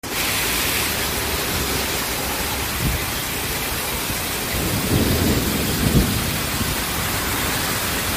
Heavy Thunderstorm Rain on public sound effects free download
Heavy Thunderstorm Rain on public Road